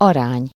Ääntäminen
US : IPA : [ˈɹeɪ.ʃi.ˌoʊ]